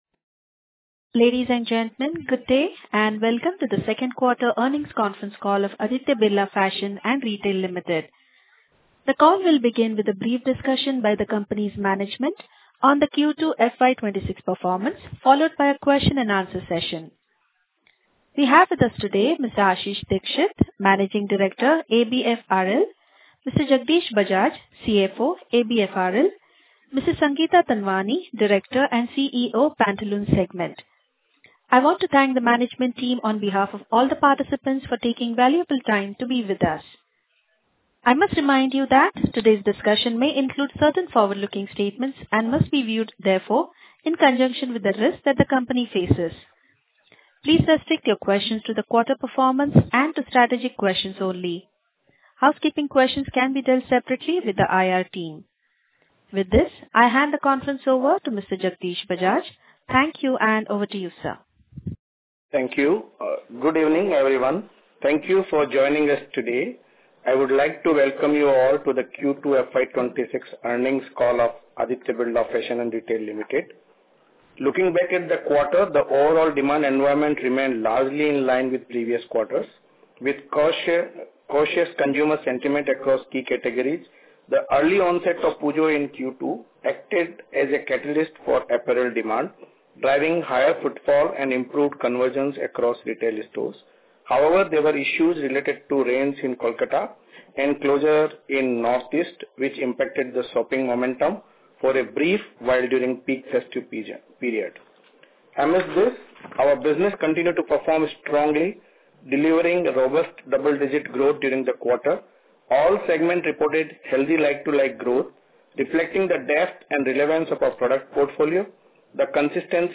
Concalls
Q2-FY26-Earnings-Recording_ABFRL.mp3